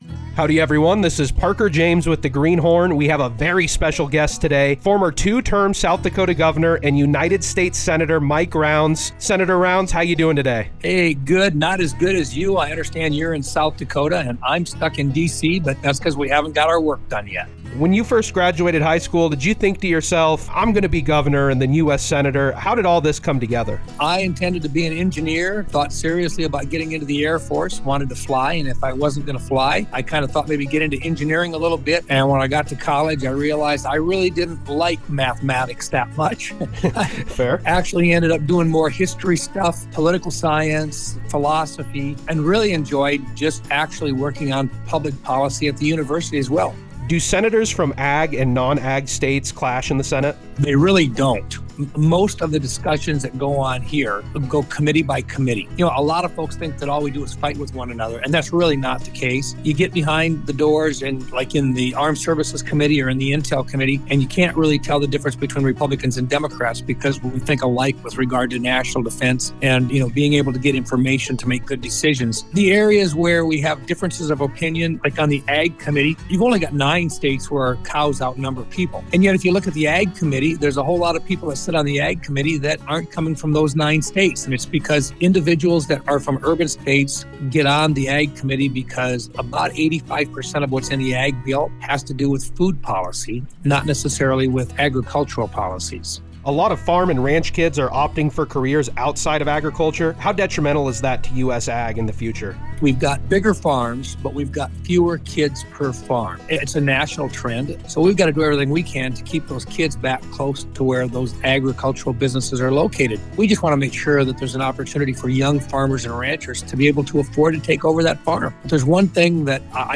Greenhorn #38: Special Interview w/ U.S. Senator Mike Rounds
YANGreenhornMikeRounds.mp3